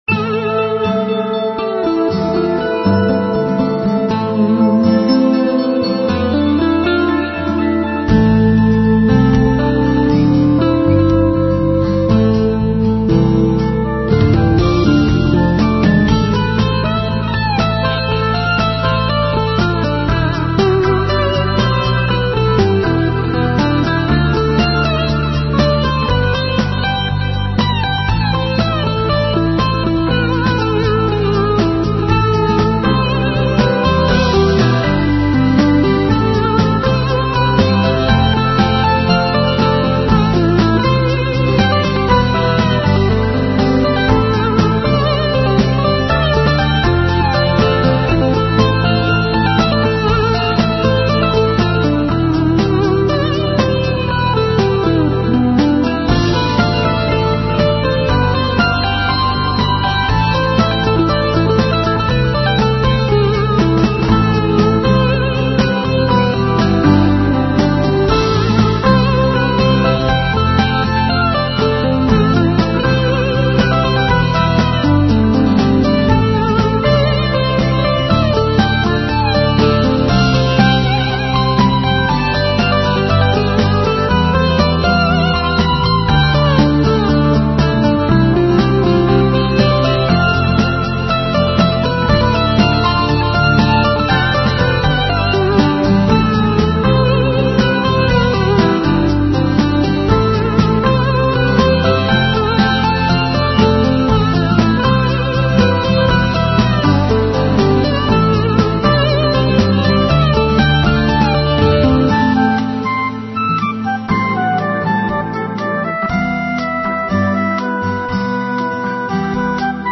Dramatic Instrumental Rock with feel of Tension